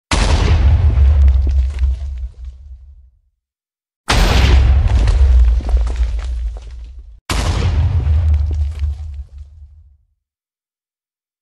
Minecraft Explosion Sound Effect Free Download